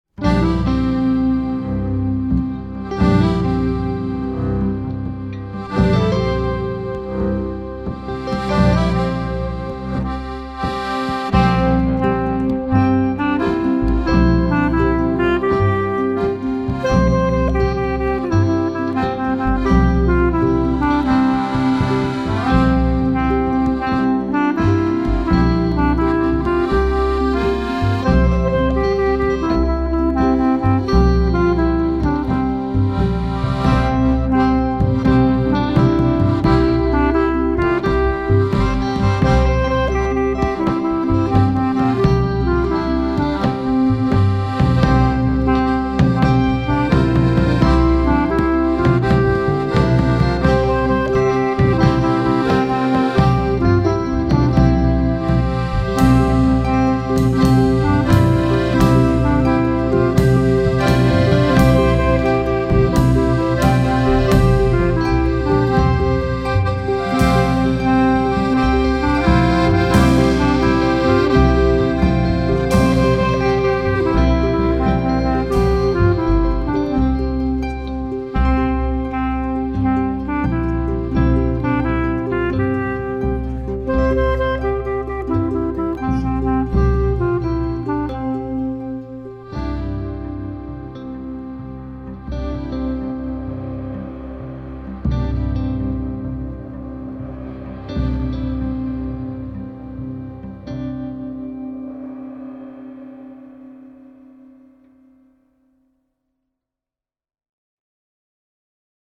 Row your Boat - Playback